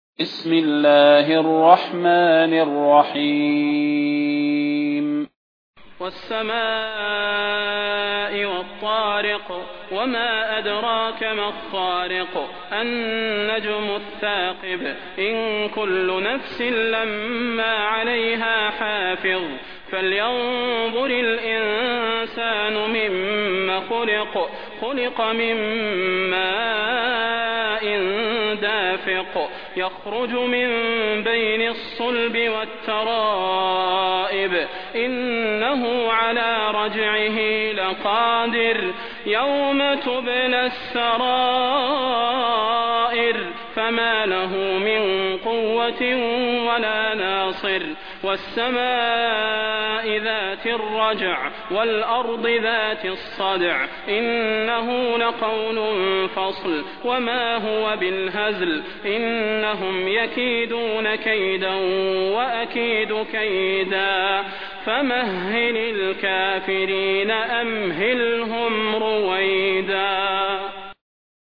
المكان: المسجد النبوي الشيخ: فضيلة الشيخ د. صلاح بن محمد البدير فضيلة الشيخ د. صلاح بن محمد البدير الطارق